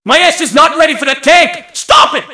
mvm_tank_alerts06.mp3